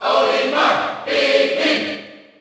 Category: Crowd cheers (SSBU) You cannot overwrite this file.